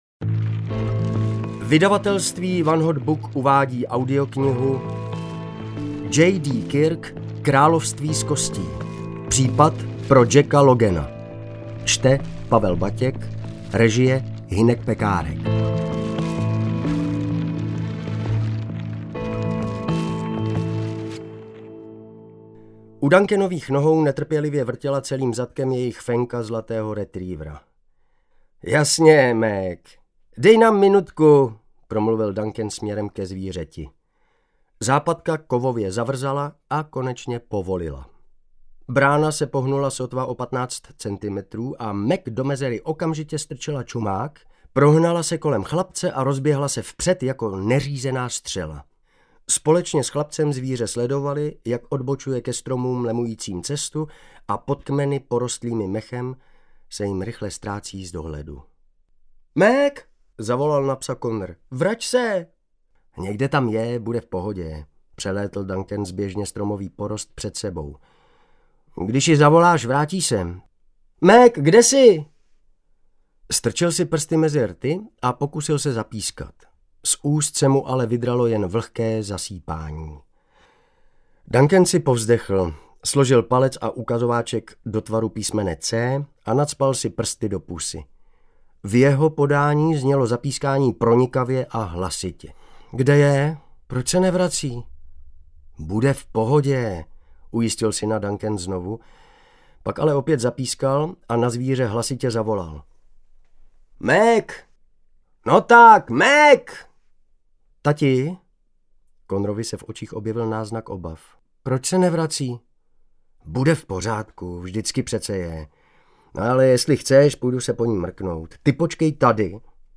Království z kostí audiokniha
Ukázka z knihy